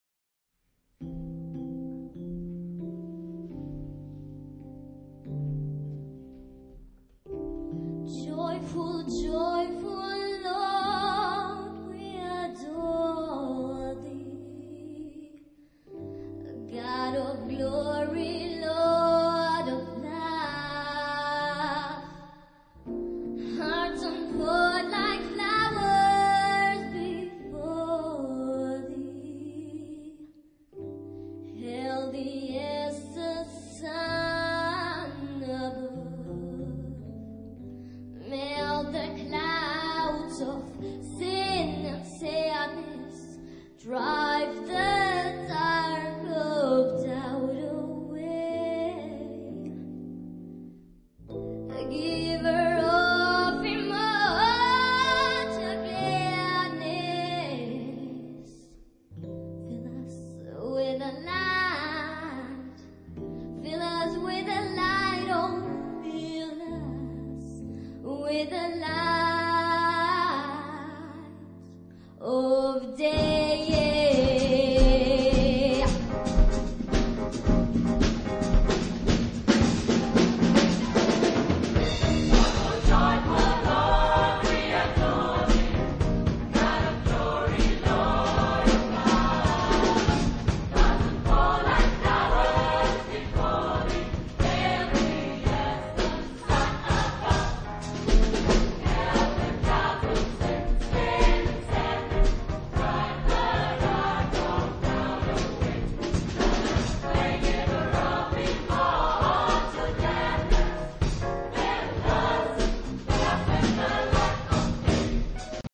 Genre-Style-Form: Choral setting ; Popsong ; Sacred ; Film music
Type of Choir: SATB  (4 mixed voices )
Soloist(s): Alt (1) OR Bass (1)  (2 soloist(s))
Tonality: C major ; E flat major ; E major
Discographic ref. : 7. Deutscher Chorwettbewerb 2006 Kiel